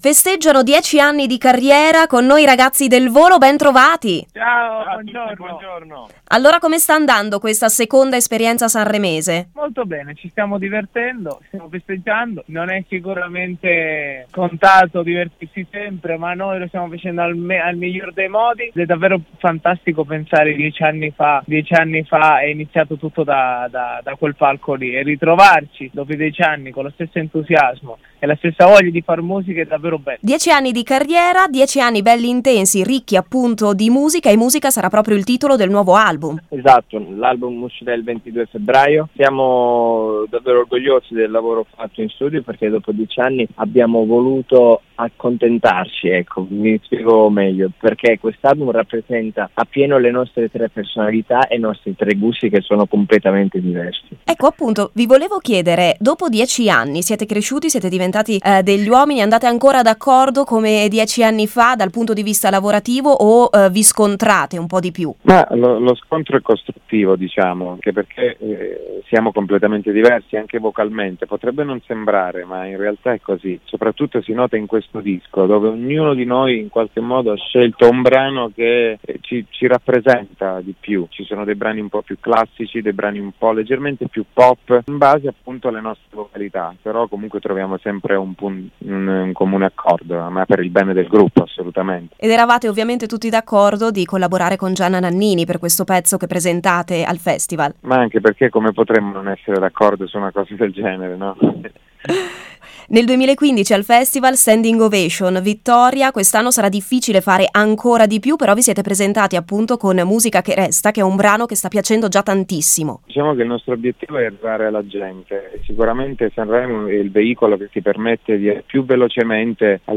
SANREMO 2019: LA NOSTRA INTERVISTA A IL VOLO